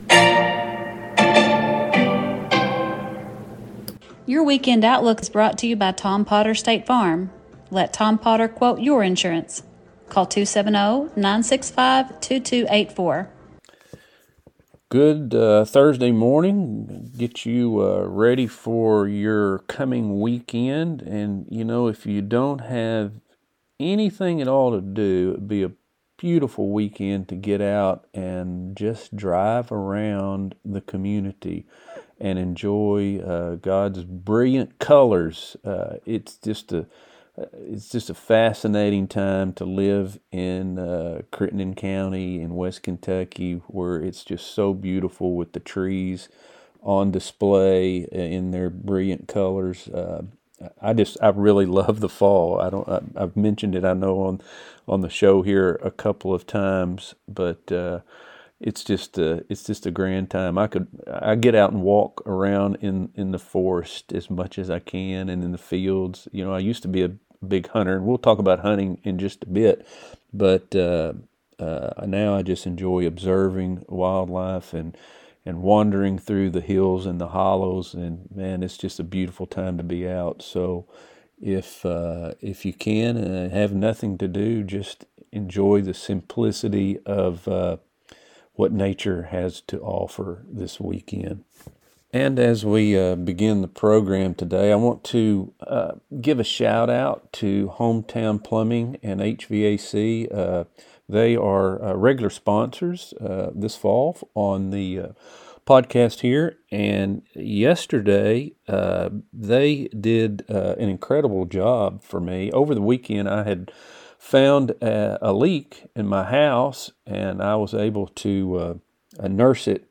STATE FARM | Thursday NEWScast